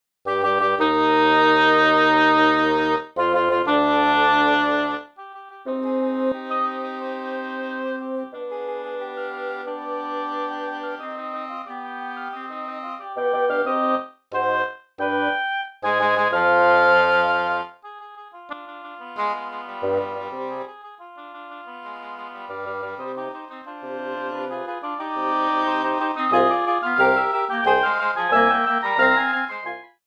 Arreglo para quinteto de doble lengüeta
Formación: 2 Oboes, 1 Corno Inglés, 2 Fagotes